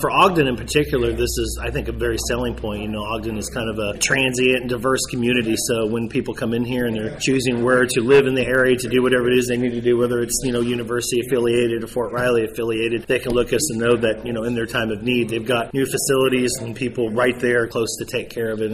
John Ford, a commissioner for Riley County, says the facility will also be beneficial for residents of Ogden.